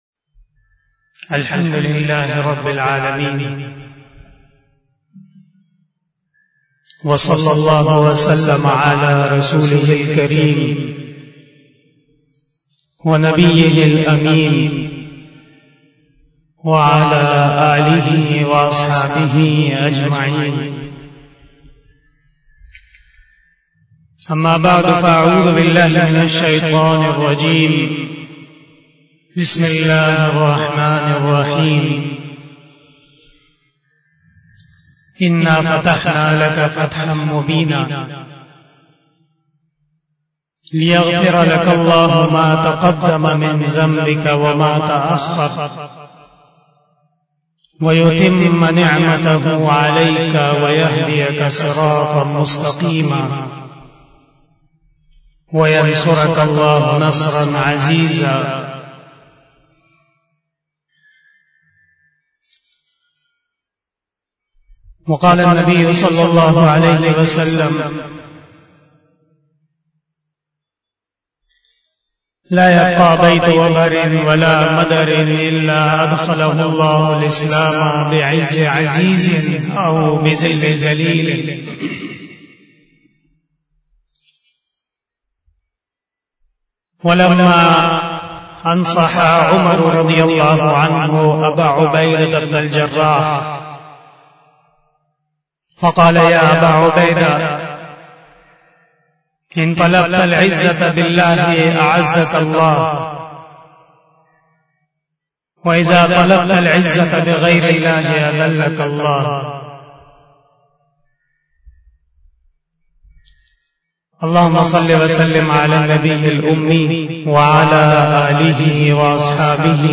34 BAYAN E JUMA TUL MUBARAK 20 August 2021 (11 Muharram 1443H)
بیان جمعۃ المبارک